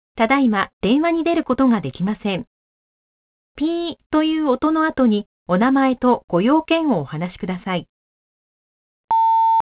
■留守番電話２